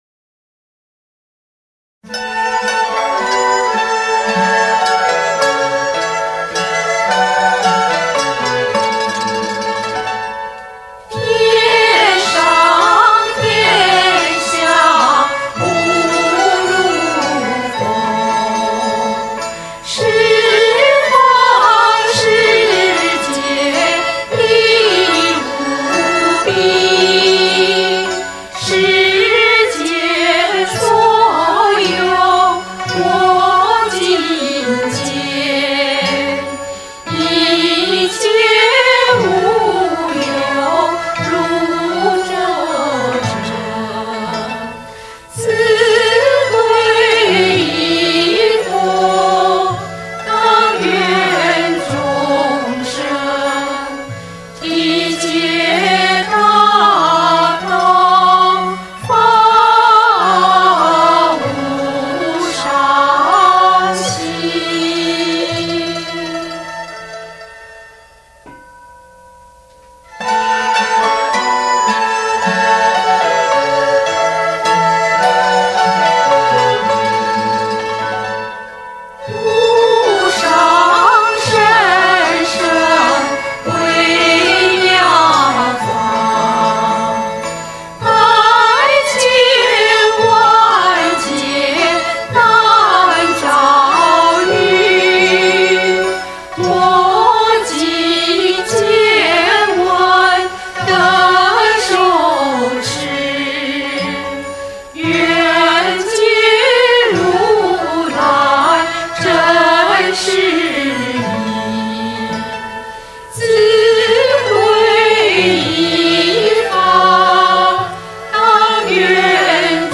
（十二）佛歌
李炳南編詞/樓永譽作曲